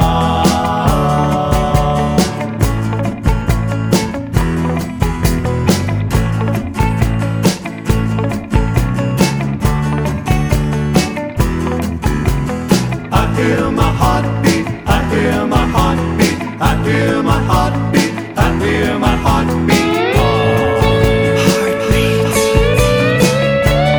One Semitone Down Rock 'n' Roll 2:48 Buy £1.50